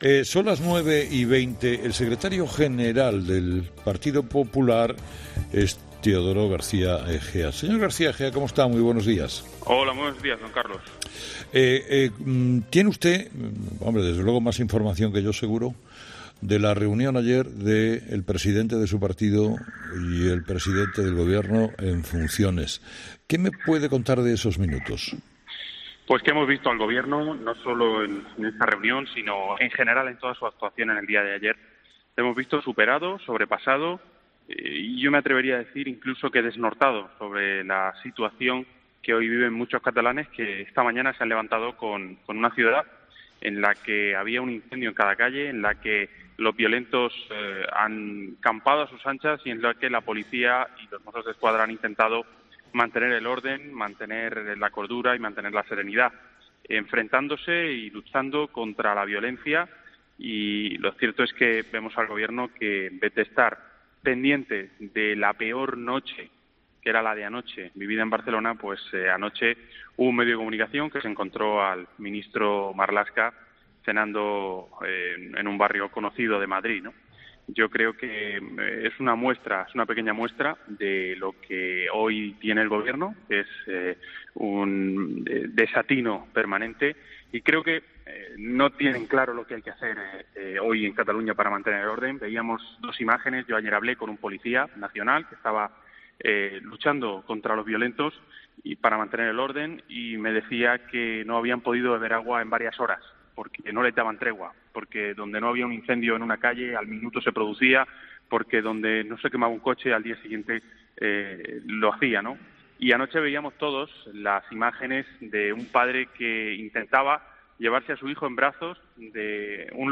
En una entrevista este jueves en 'Herrera en COPE', García Egea ha razonado su petición de dimisión.